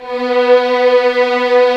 Index of /90_sSampleCDs/Roland L-CD702/VOL-1/STR_Vlns 6 mf-f/STR_Vls6 f slo